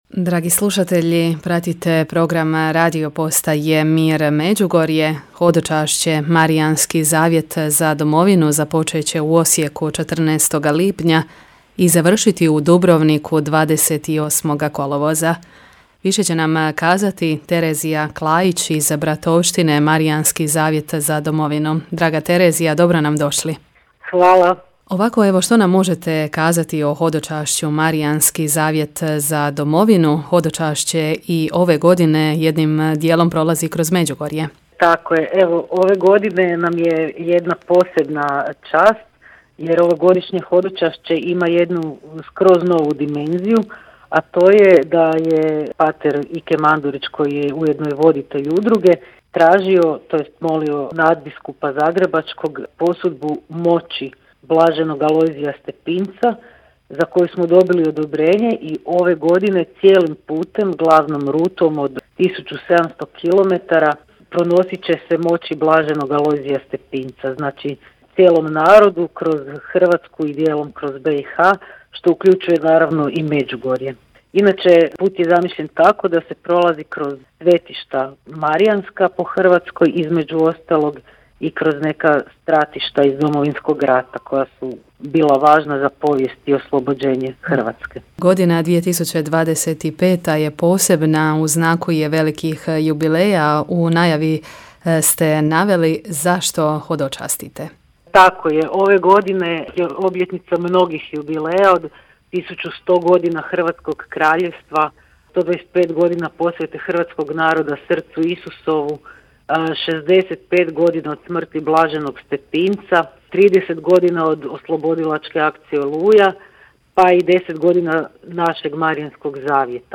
razgovora